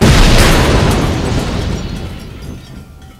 explode_2.wav